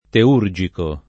teurgico [ te 2 r J iko ] agg.; pl. m. ‑ci